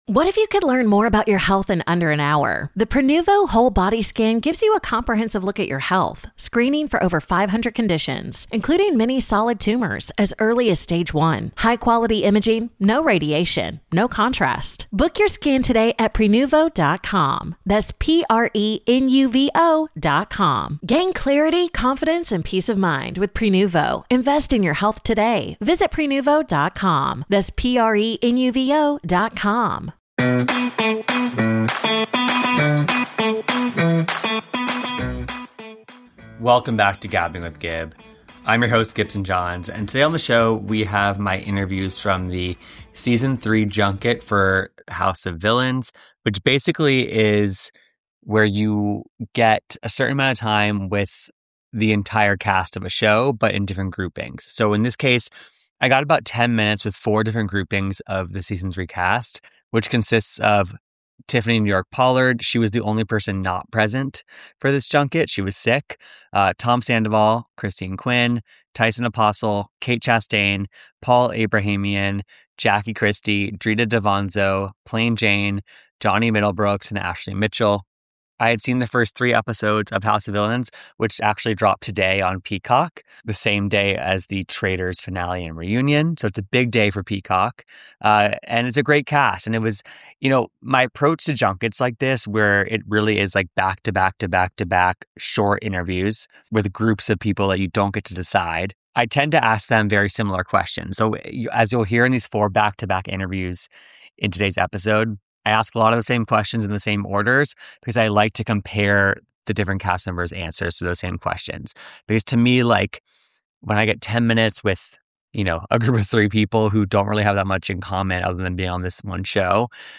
'House of Villains' Season 3 Junket: Interviews with Kate Chastain, Christine Quinn, Drita D'Avanzo, Tom Sandoval and More